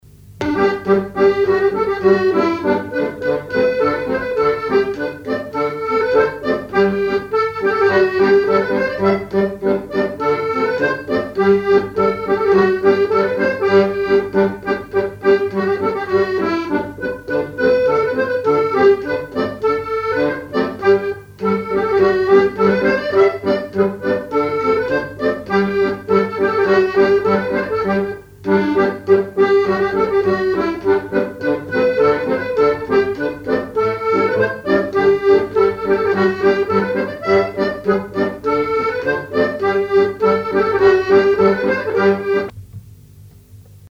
Chants brefs - A danser
danse : scottich sept pas
accordéon chromatique
Pièce musicale inédite